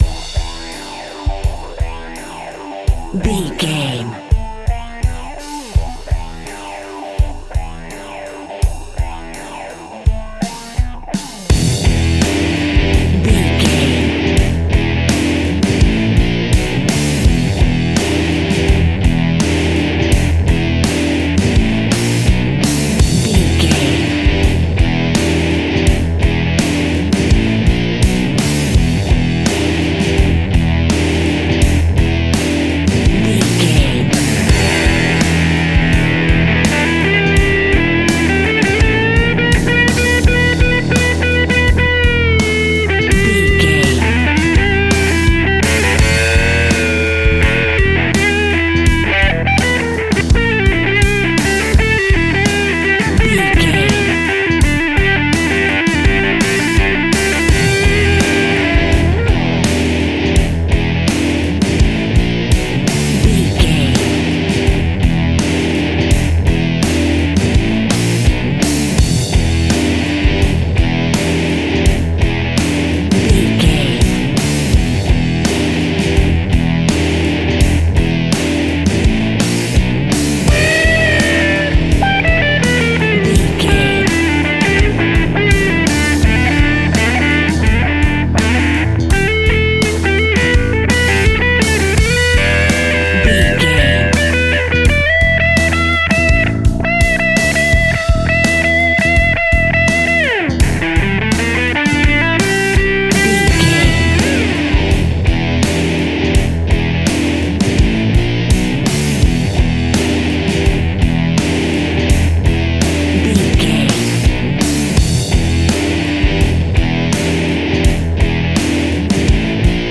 Epic / Action
Fast paced
Aeolian/Minor
hard rock
blues rock
distortion
instrumentals
Rock Bass
heavy drums
distorted guitars
hammond organ